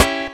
Snare (Criminal) 2.wav